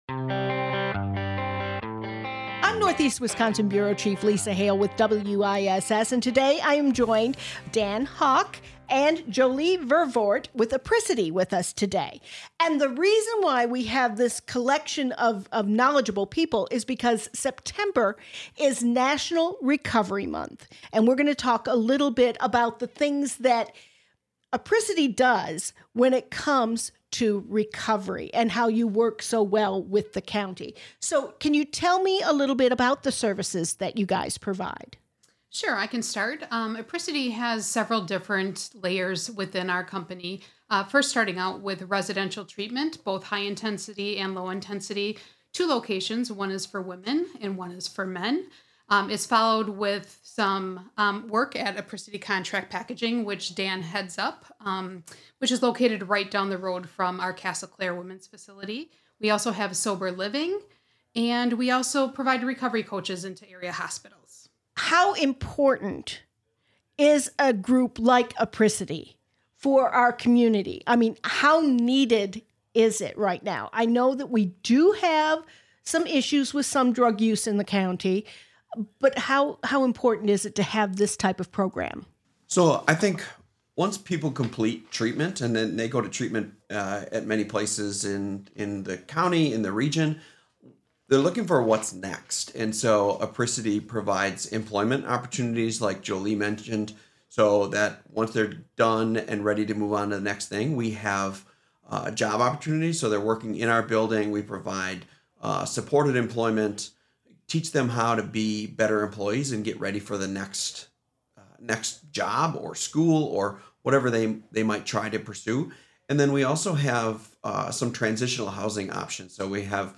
The three have a two part discussion on the work Apricity is doing this September, and throughout the year, when it comes to recovery in the Fox Valley.